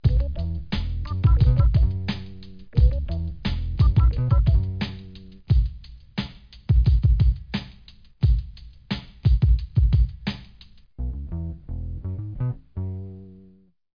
Sound Factory: RAP